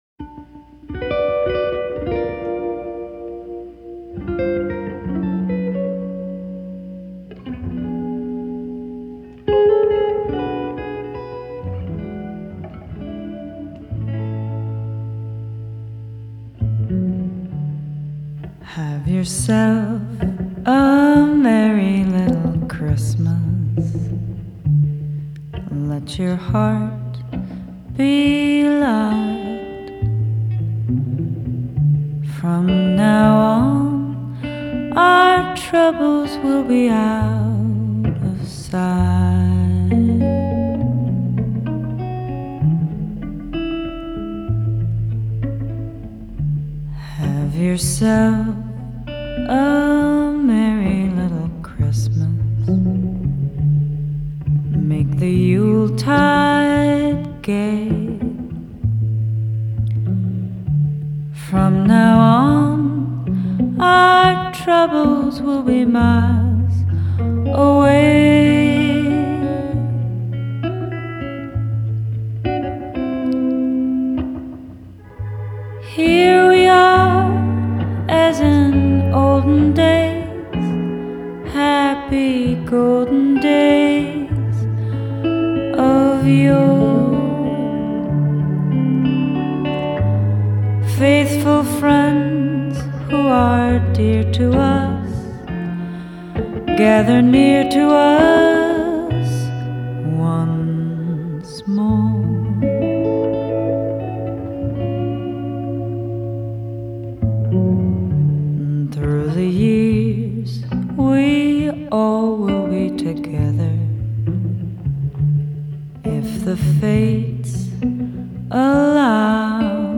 Style: Indie Folk